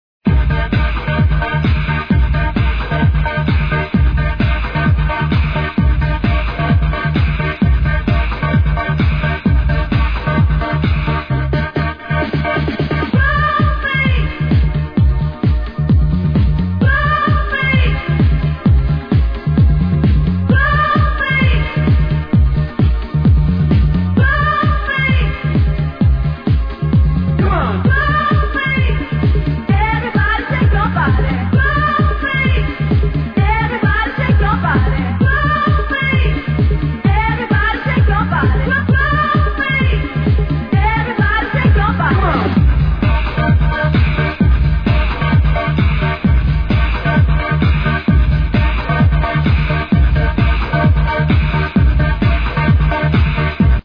Proper old skool c1990/91 tune to be ID'no.3!